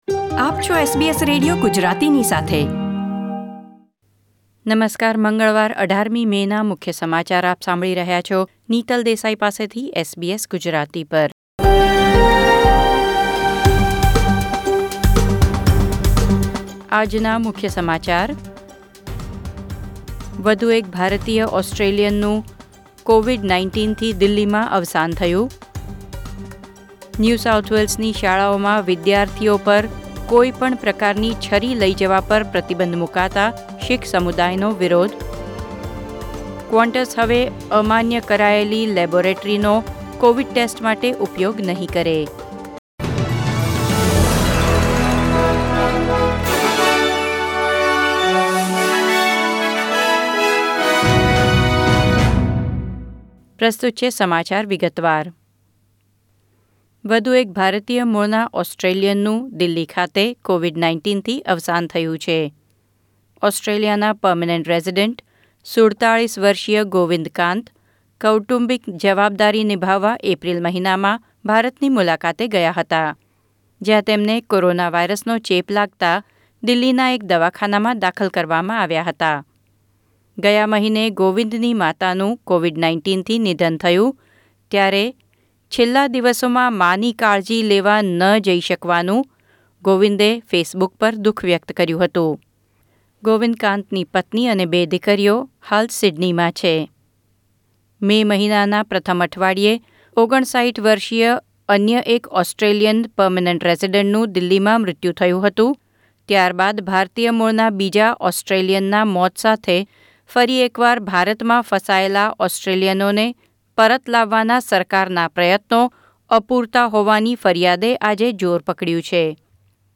SBS Gujarati News Bulletin 18 May 2021